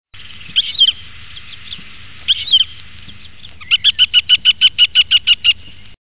Busard des roseaux
Circus aeruginosus
busard.mp3